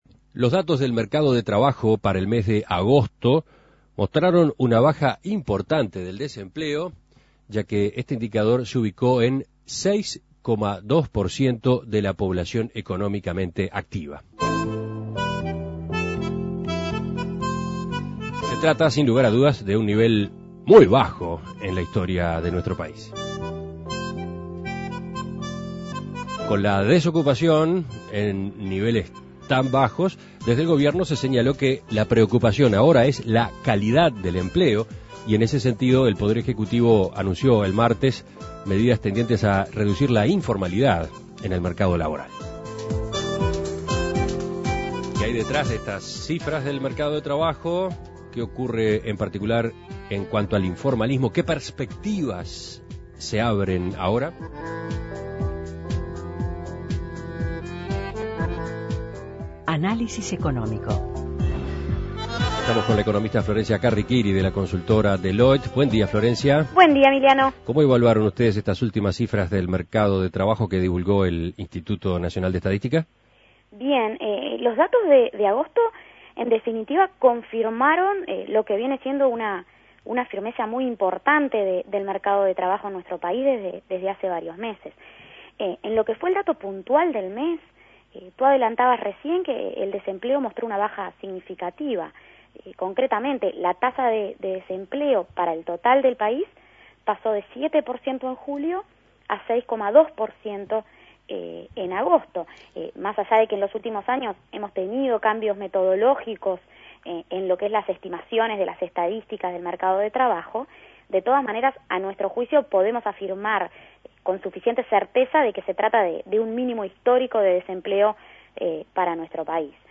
Análisis Económico El desempleo alcanzó mínimos históricos en agosto.